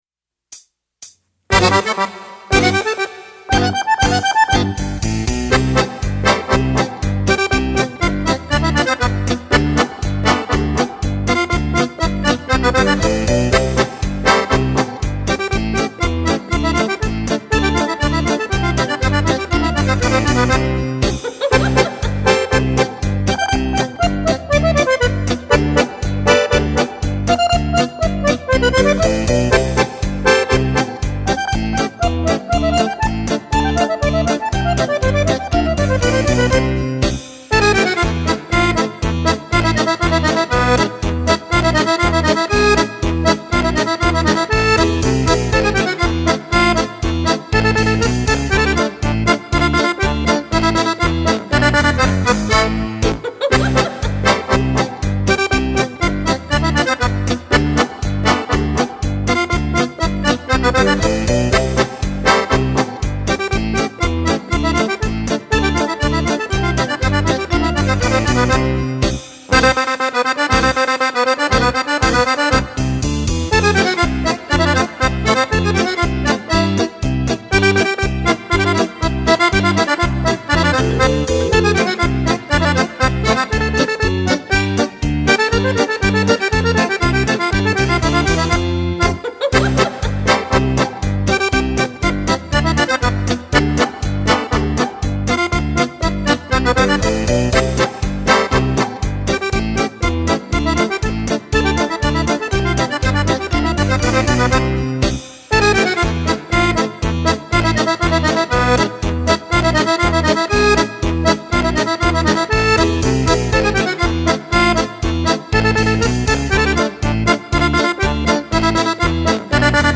Polka
Polka per fisarmonica